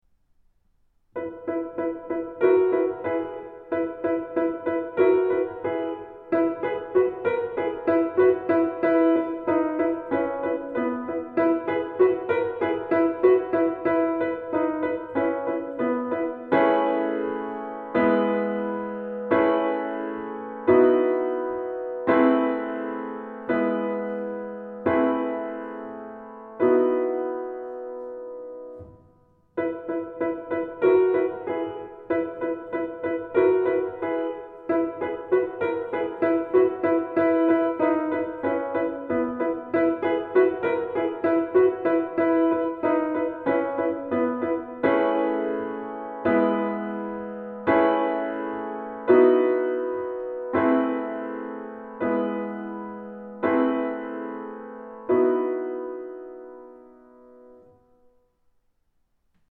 Genre :  ChansonComptine
Audio Piano seul